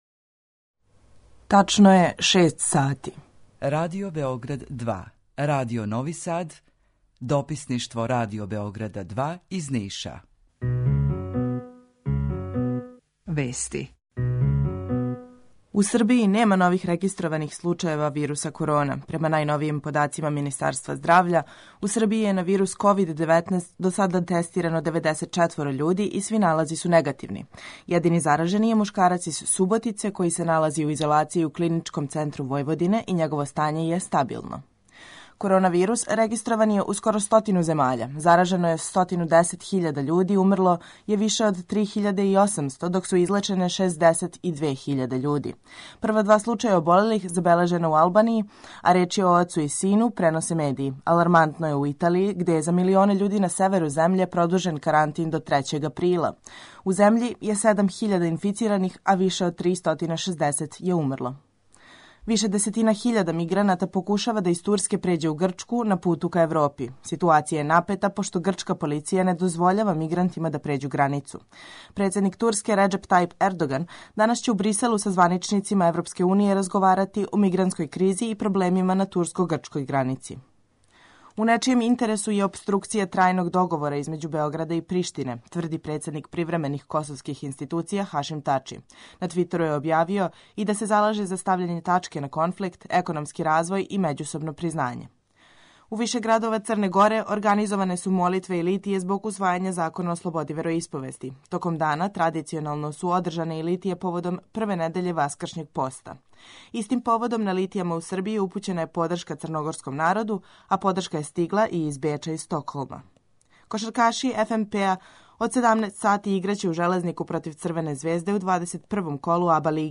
Jутарњи програм заједнички реализују Радио Београд 2, Радио Нови Сад и дописништво Радио Београда из Ниша.
Јутарњи програм из три студија